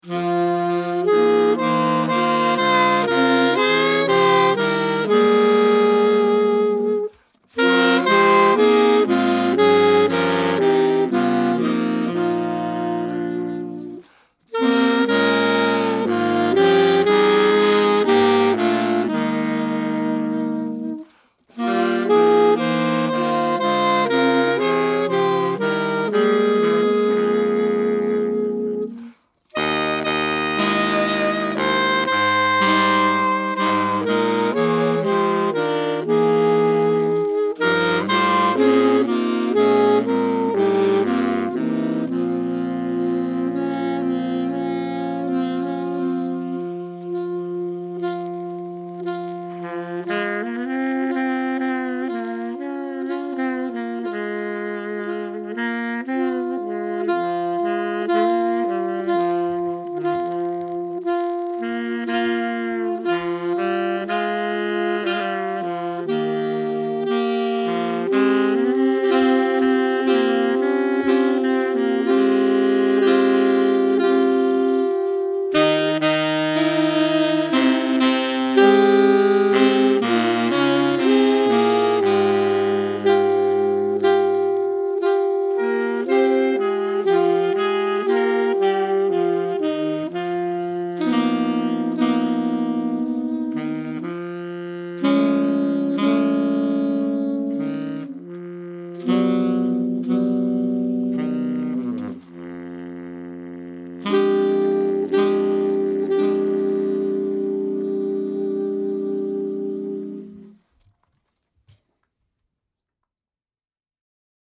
Voicing: 4 Sax